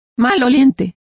Complete with pronunciation of the translation of smelly.